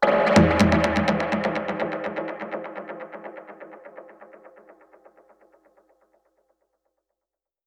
Index of /musicradar/dub-percussion-samples/125bpm
DPFX_PercHit_B_125-07.wav